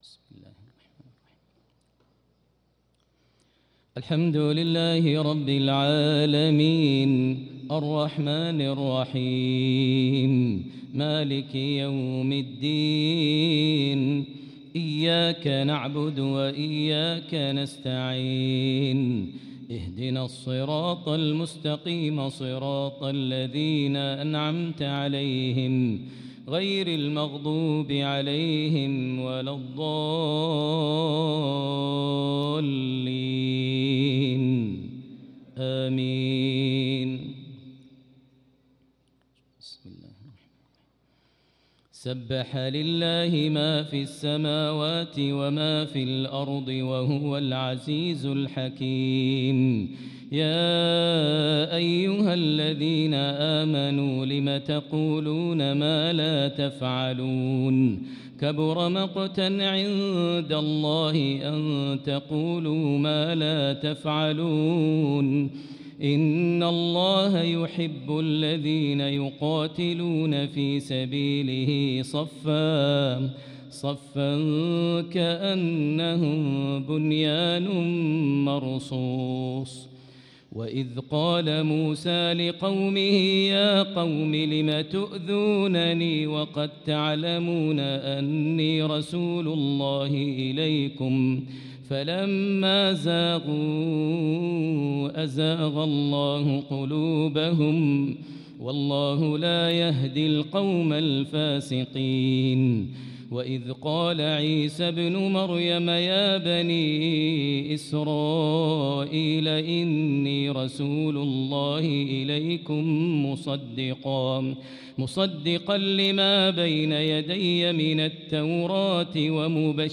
صلاة العشاء للقارئ ماهر المعيقلي 3 شعبان 1445 هـ
تِلَاوَات الْحَرَمَيْن .